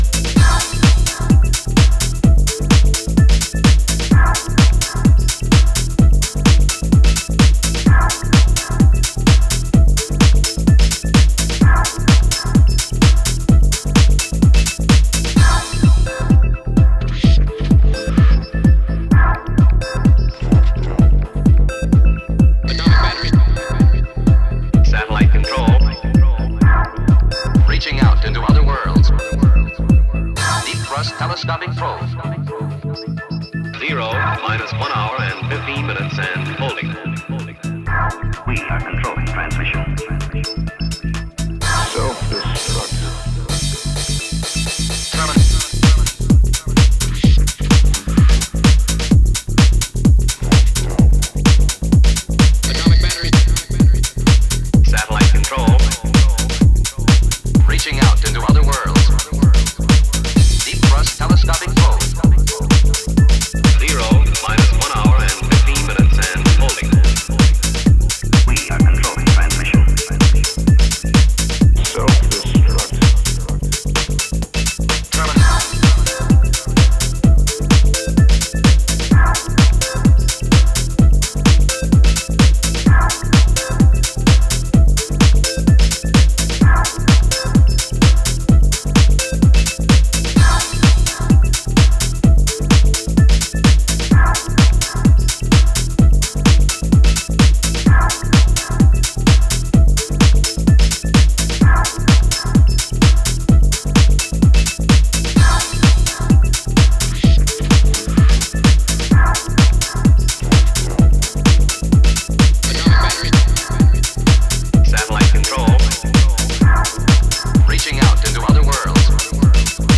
滑らかなコードとハイトーンなリフを手堅いグルーヴで引き締めた